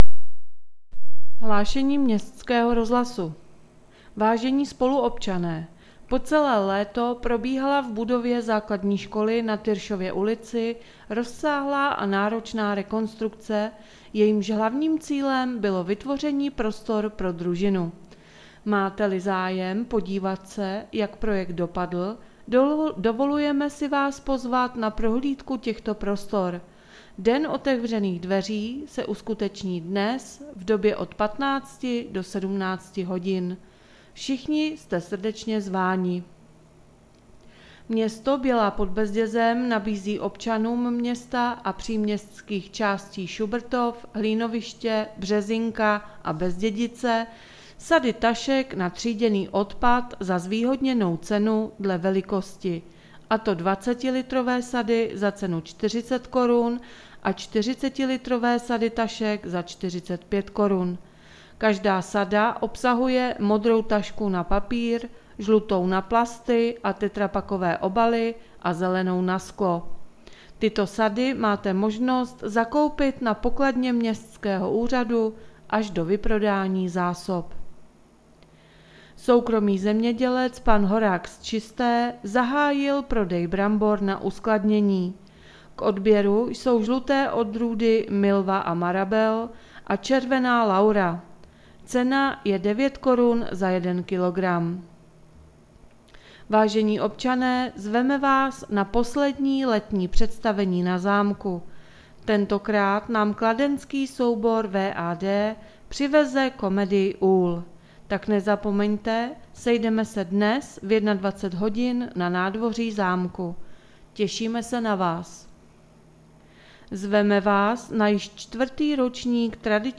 Hlášení městského rozhlasu 4.9.2020